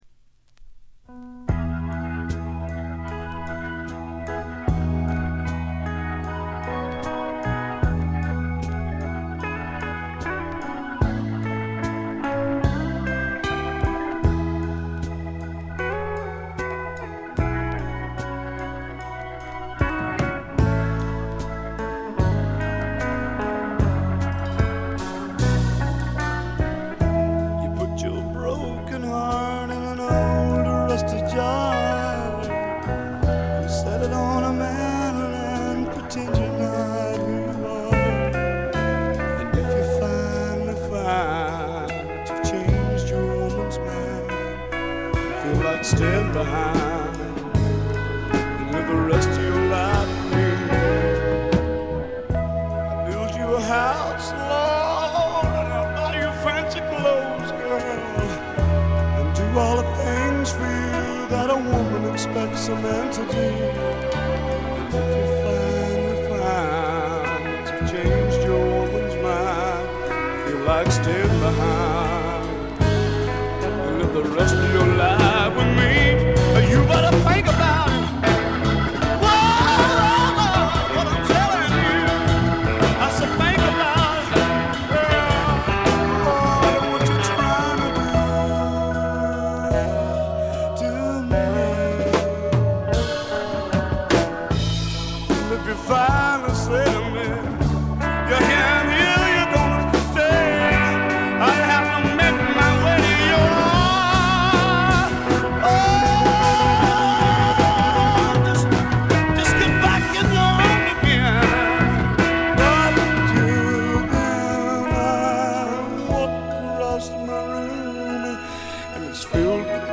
濃厚なスワンプ味に脳天直撃される傑作です。
試聴曲は現品からの取り込み音源です。